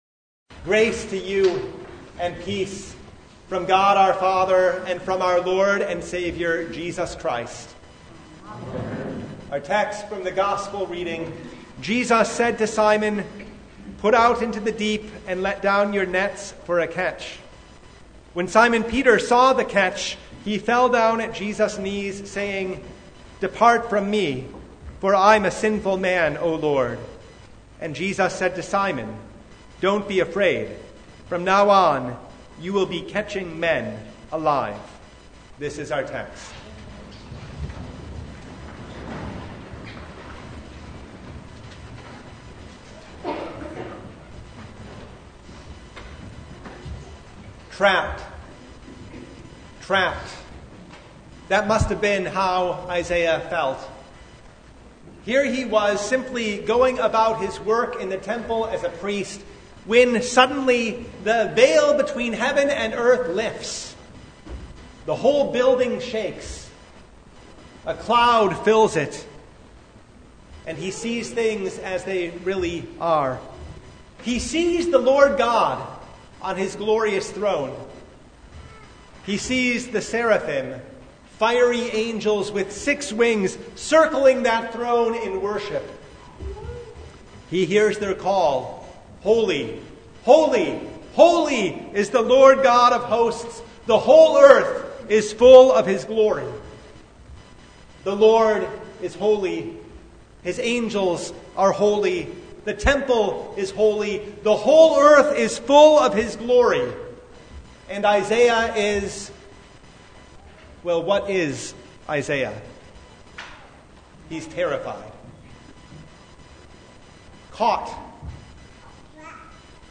Luke 5:1-11 Service Type: Sunday Isaiah was trapped.